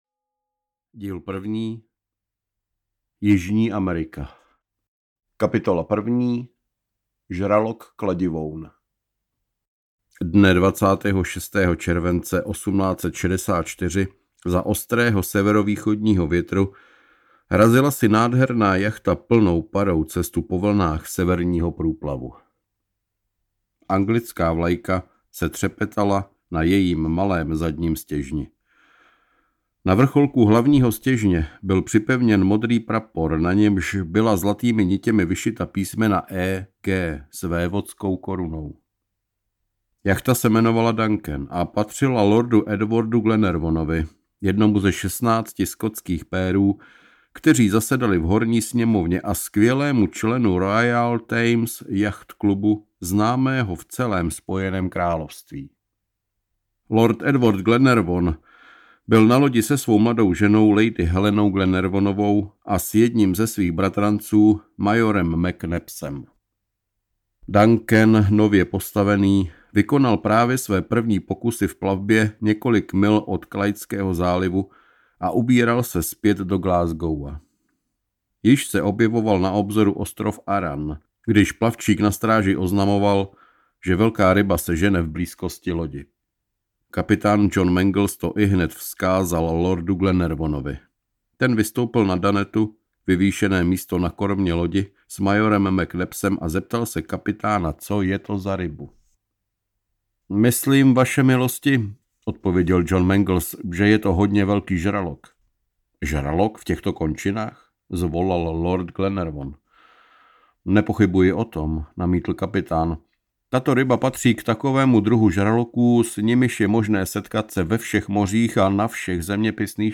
Děti kapitána Granta audiokniha
Ukázka z knihy